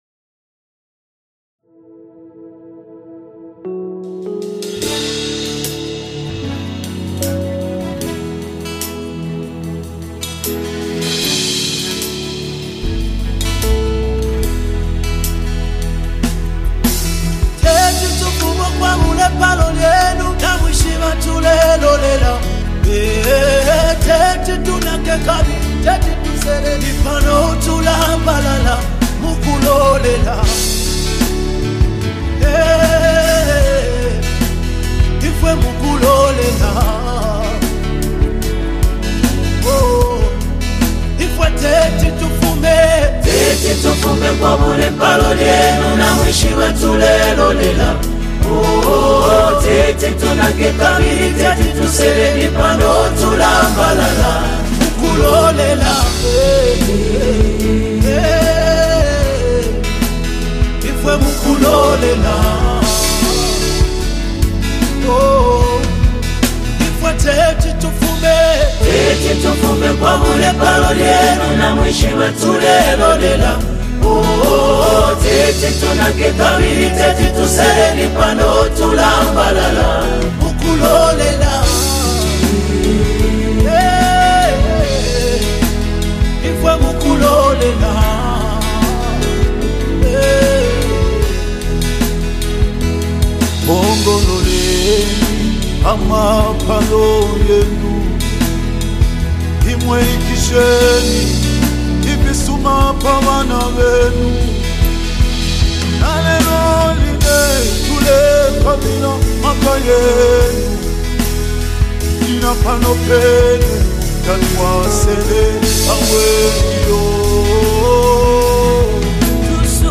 Latest Zambian Gospel Music 2025